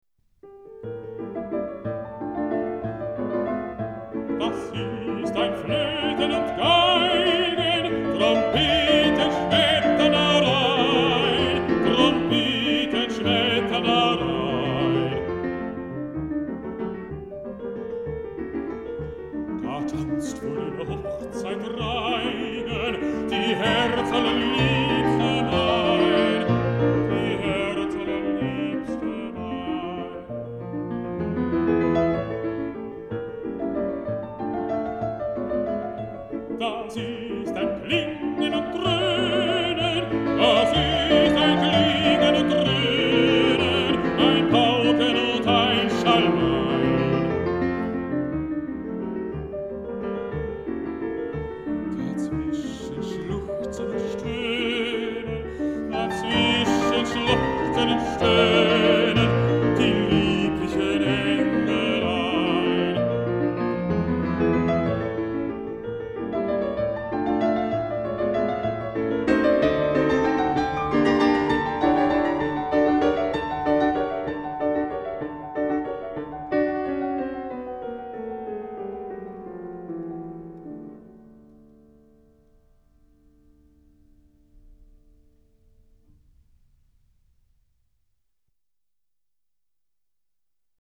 Música vocal
Música clásica